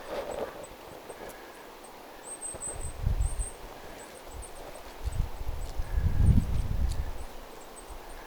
hippiäisten ääntelyä ruovikossa
hippiaisia_ruovikossa.mp3